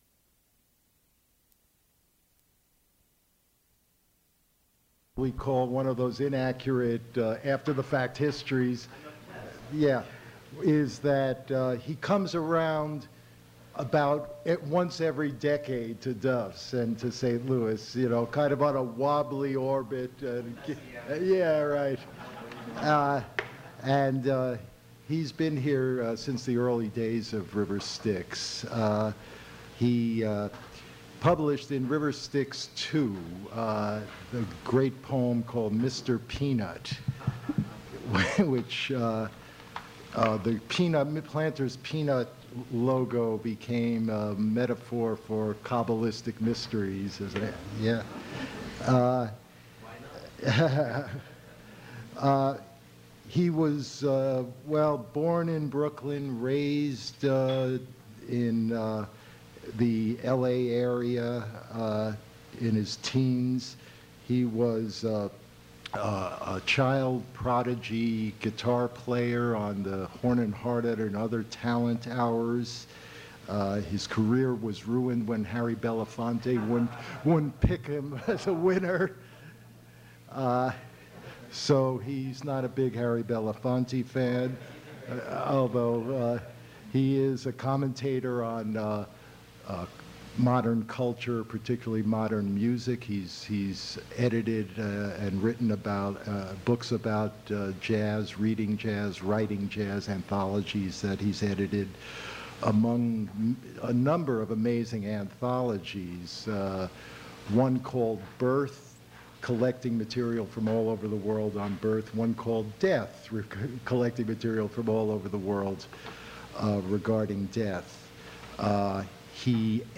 Attributes Attribute Name Values Description David Meltzer poetry reading at Duff's Restaurant.
mp3 edited access file was created from unedited access file which was sourced from preservation WAV file that was generated from original audio cassette.
audio stops abruptly in the middle of last poem